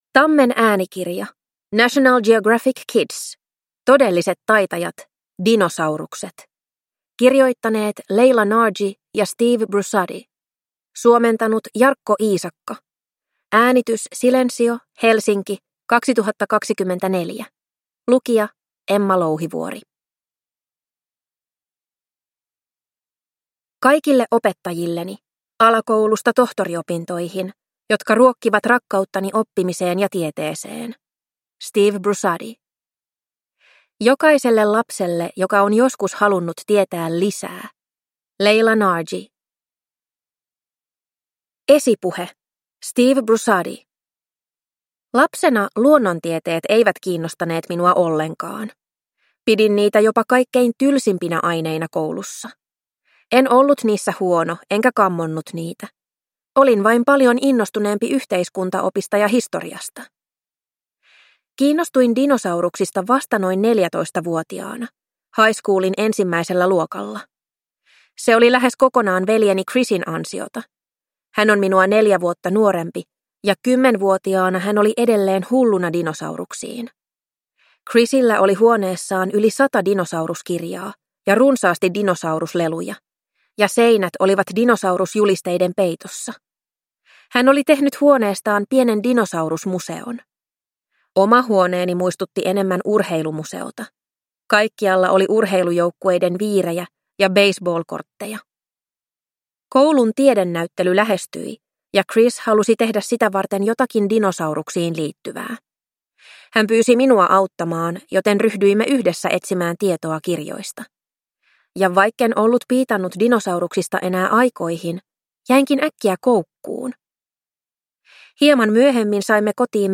Todelliset taitajat. Dinosaurukset – Ljudbok